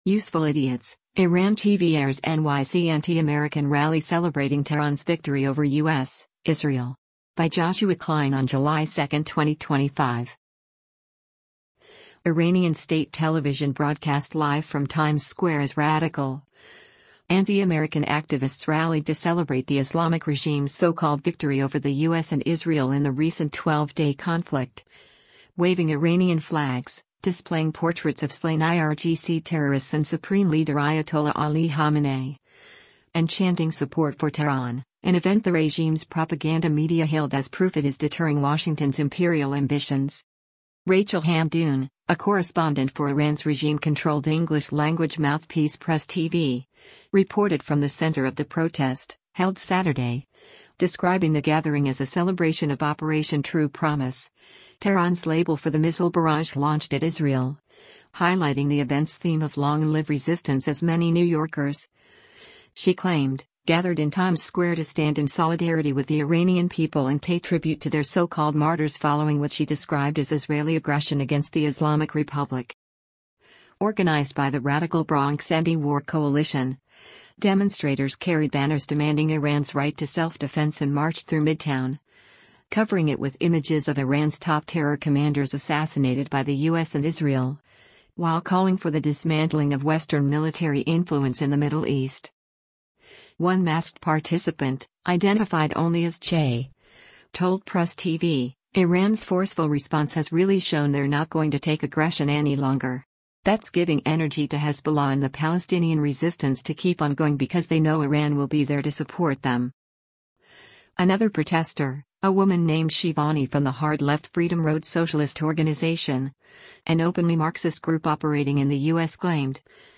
Iranian state television broadcast live from Times Square as radical, anti-American activists rallied to celebrate the Islamic regime’s so-called “victory” over the U.S. and Israel in the recent 12-day conflict—waving Iranian flags, displaying portraits of slain IRGC terrorists and Supreme Leader Ayatollah Ali Khamenei, and chanting support for Tehran—an event the regime’s propaganda media hailed as proof it is deterring Washington’s “imperial ambitions.”